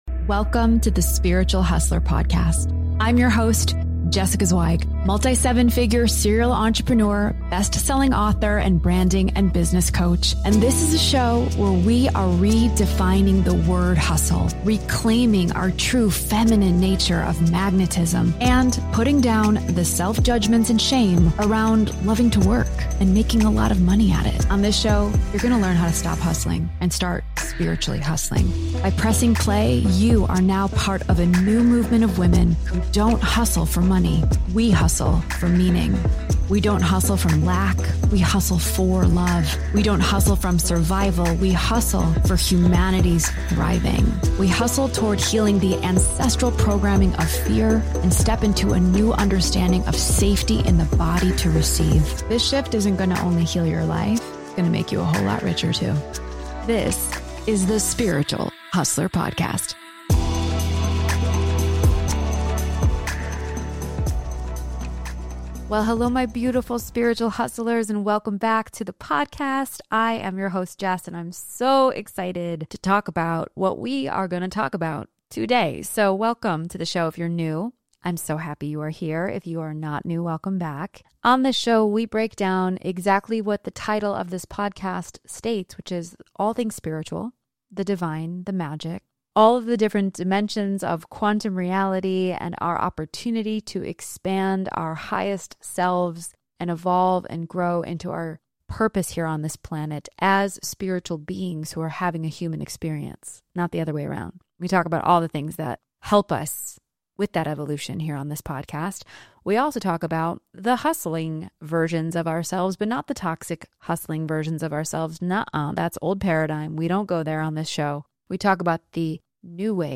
Through raw solo episodes and captivating interviews with the most inspiring female leaders of today, you will discover what it looks like to hustle from a new vibration of love, trust, meaning and service to humanity, and how it can heal your life.